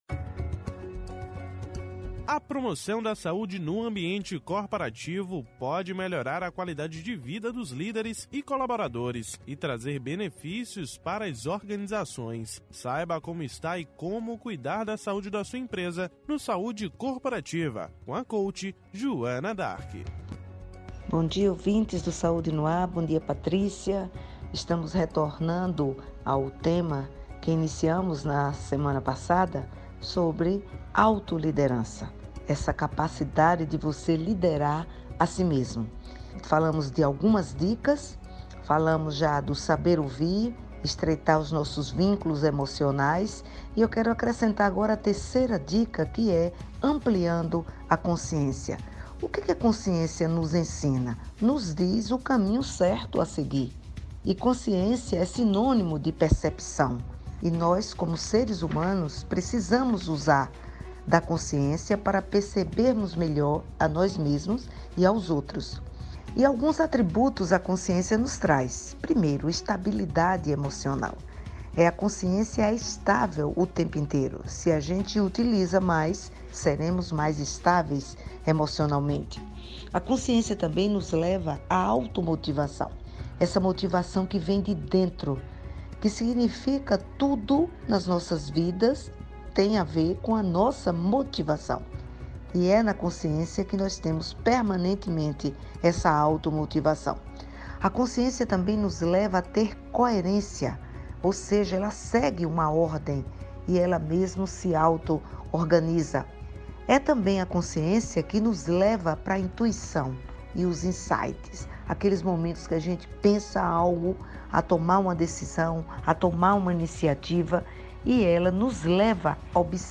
O assunto foi tema do Quadro “Saúde Corporativa” desta segunda-feira (09/10), no Programa Saúde no Ar.